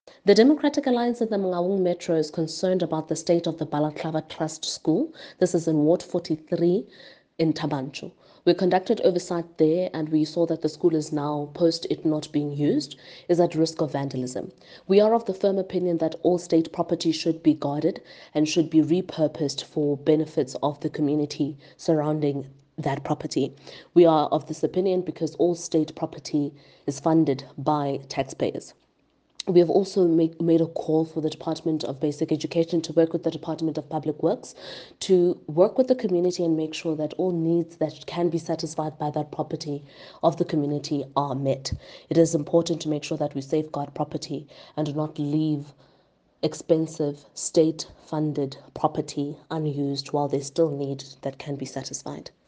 English and Sesotho soundbites by Karabo Khakhau MP.